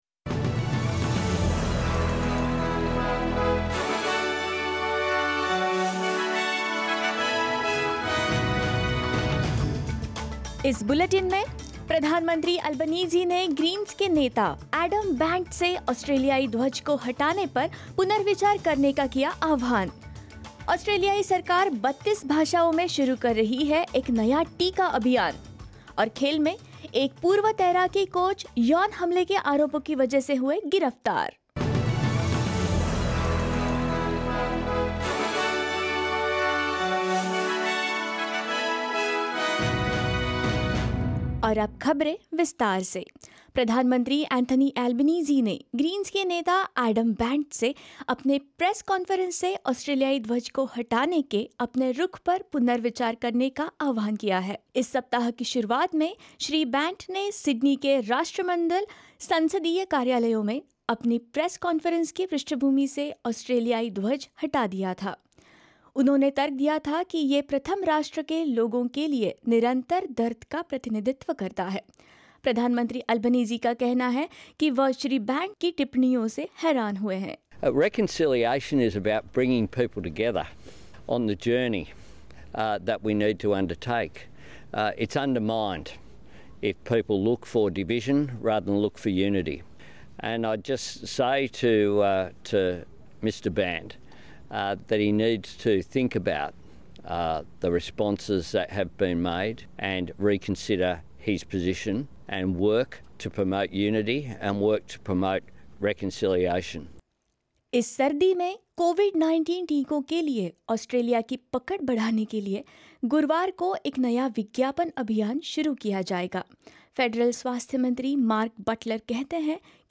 In this latest SBS Hindi bulletin: Prime Minister Anthony Albanese calls Greens leader Adam Bandt to reconsider his decision to remove the Australian flag from press conferences; The government set to roll out a new vaccine campaign in 32 languages other than English; Bharatiya Janata party-led NDA announces Draupadi Murmu's name as Presidential candidate and more